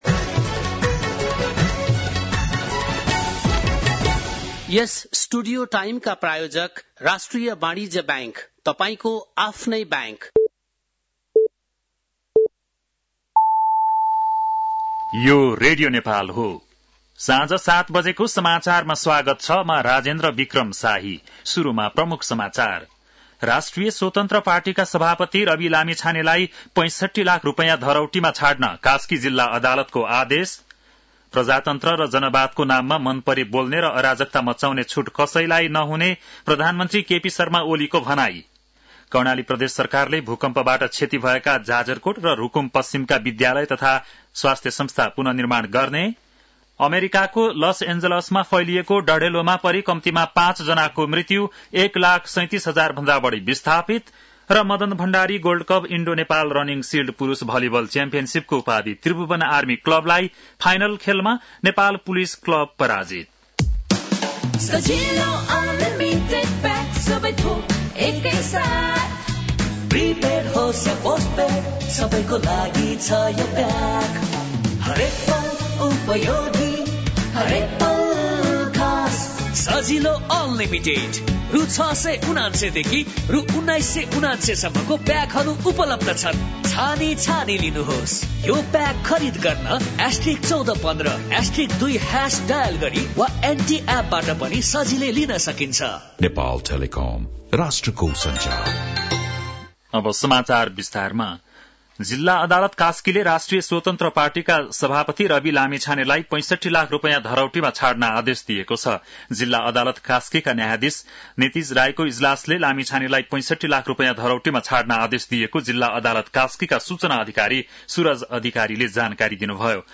बेलुकी ७ बजेको नेपाली समाचार : २६ पुष , २०८१
7-PM-Nepali-NEWS-9-25.mp3